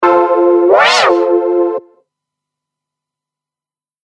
| pirate effect |